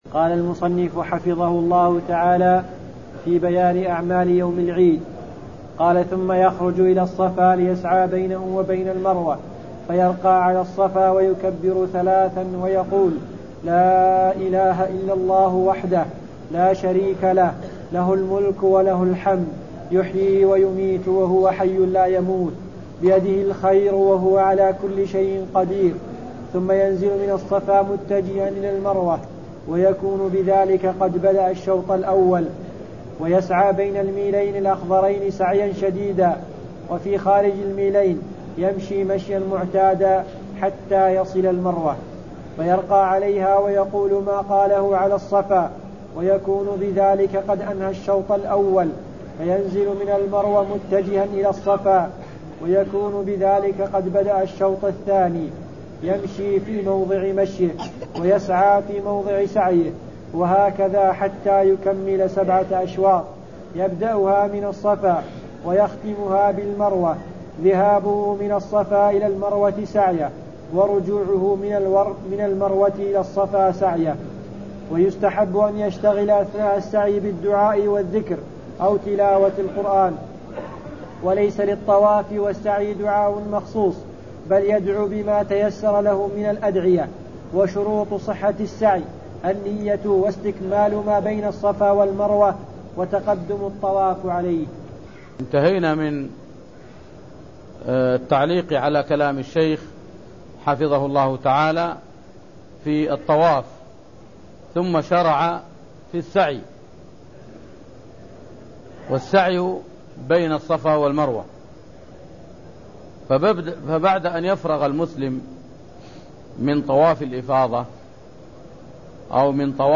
المكان: المسجد النبوي الشيخ: فضيلة الشيخ د. صالح بن سعد السحيمي فضيلة الشيخ د. صالح بن سعد السحيمي كتاب الحج-باب-أعمال يوم العيد (0009)